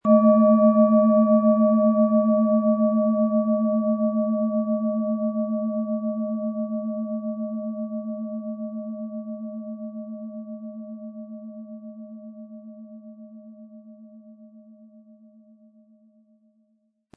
Thetawelle
Der richtige Schlegel ist umsonst dabei, er lässt die Klangschale voll und angenehm erklingen.
PlanetentöneThetawelle & Alphawelle
SchalenformBihar
MaterialBronze